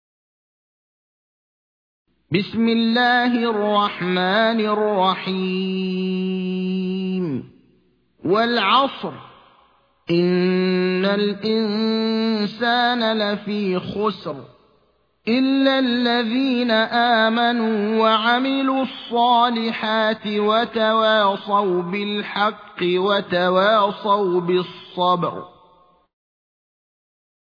قرآن - قاری إبراهيم الأخضر